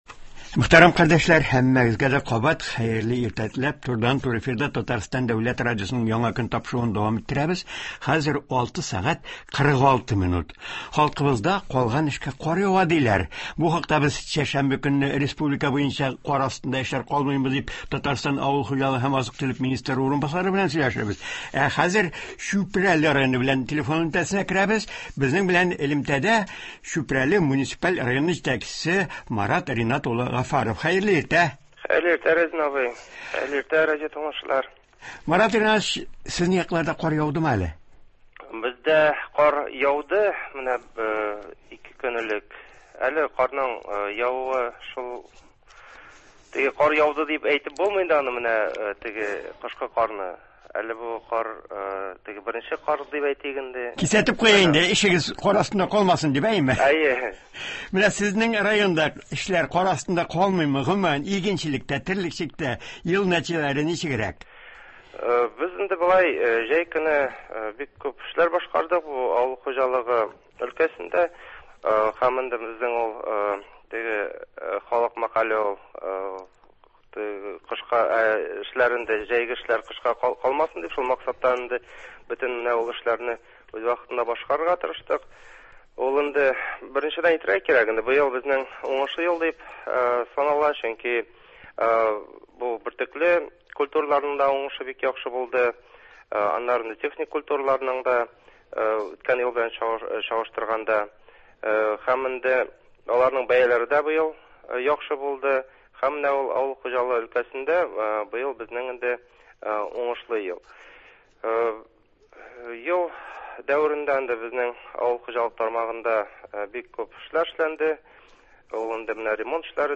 Күпчелек районнарда кар ява башлады, әмма Чүпрәле районында кар астында башкарылмаган эшләр калдырмаска тырышалар. Шикәр чөгендере кырларында әлегә озатылмаган продукция шактый булса да, борчылмыйлар, чөнки салкын температурада аның шикәрлелеге арта бара. Болар хакында телефон элемтәсе аша Чүпрәле муниципаль районы җитәкчесе Марат Гаффаров сөйләячәк.